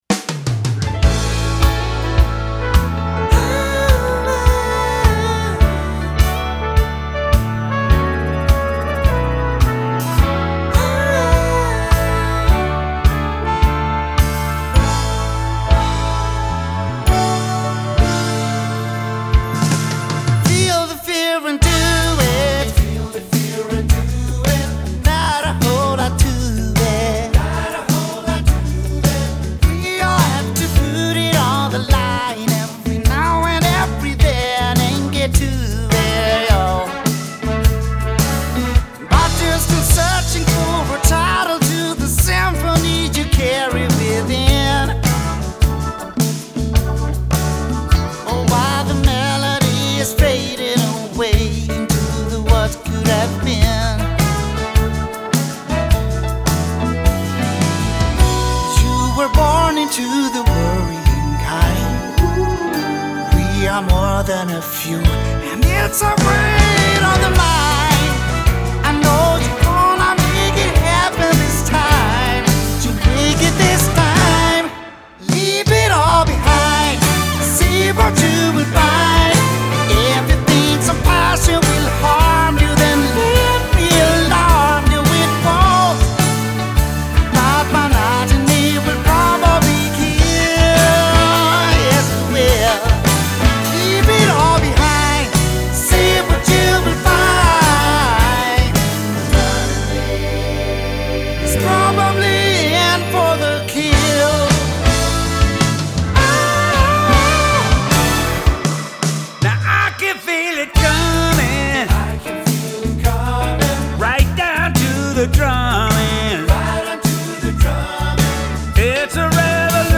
Great drum performances with all Class A mics & preamps, great kit, pro recording studio
Pop Rock Funk Blues Country